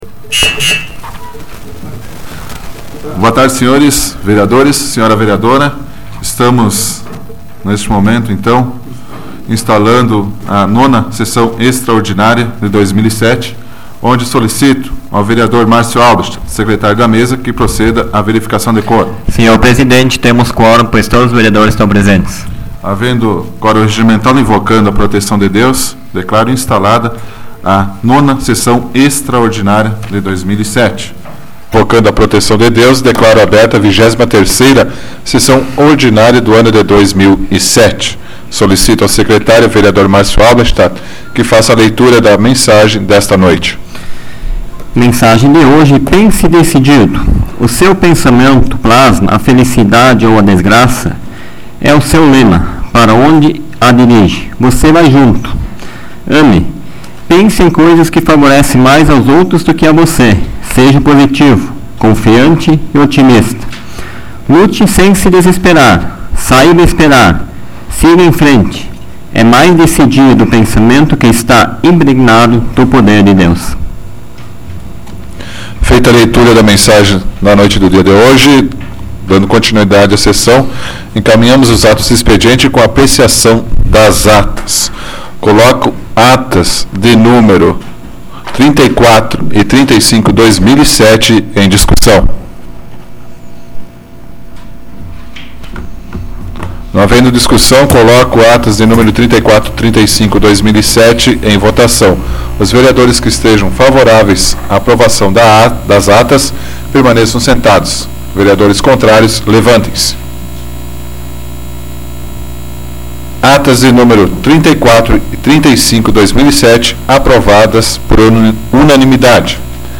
Áudio da 97ª Sessão Plenária Ordinária da 12ª Legislatura, de 10 de setembro de 2007